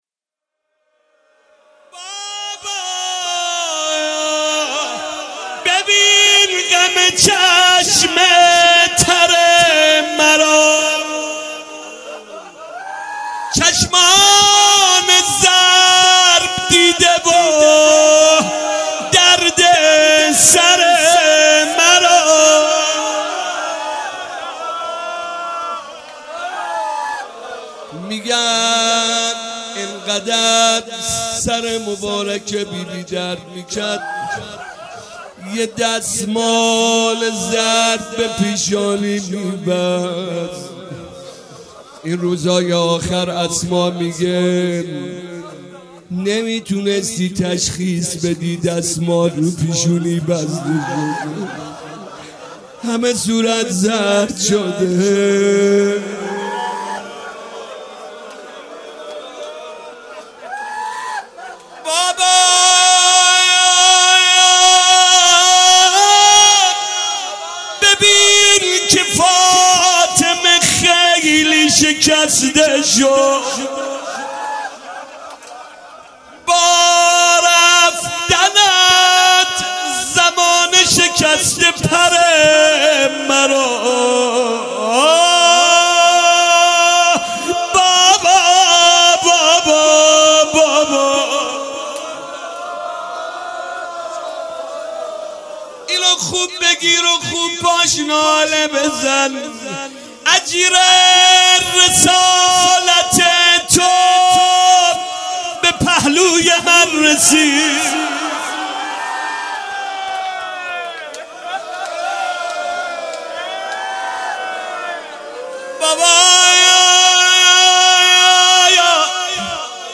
فاطمیه 95 - شب سوم - روضه - بابا ببین غم چشم تر مرا